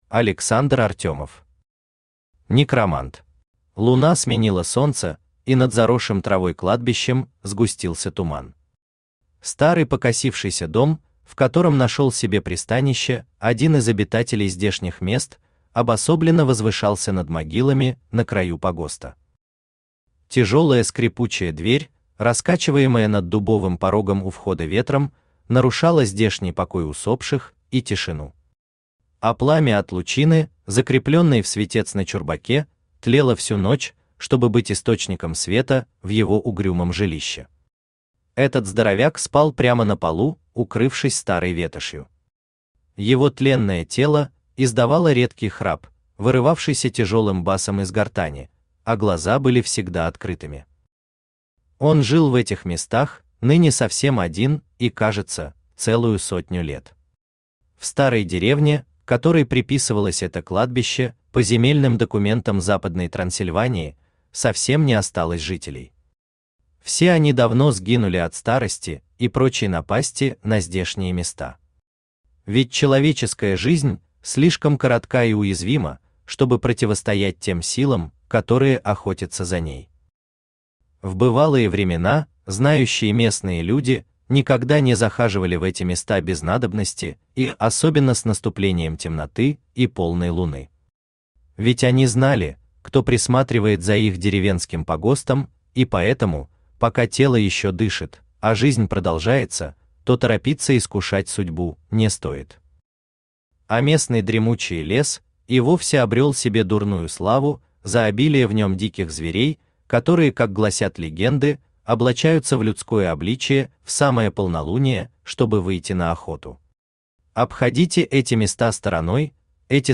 Аудиокнига Некромант | Библиотека аудиокниг
Aудиокнига Некромант Автор Александр Артемов Читает аудиокнигу Авточтец ЛитРес.